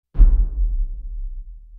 Звуки boom
Тыдыщь